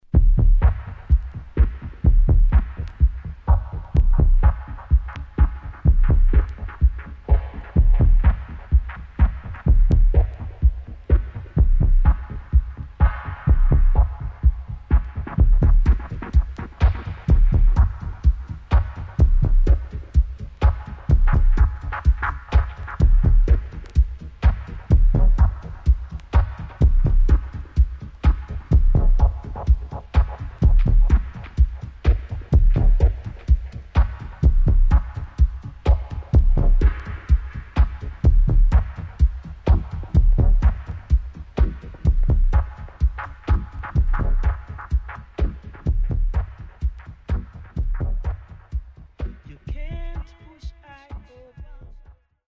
2003.Abstract Dope Dub with Female Vocal